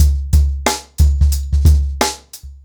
TrackBack-90BPM.75.wav